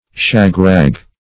Search Result for " shag-rag" : The Collaborative International Dictionary of English v.0.48: Shag-rag \Shag"-rag`\, n. The unkempt and ragged part of the community.